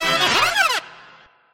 Grito
901cry.mp3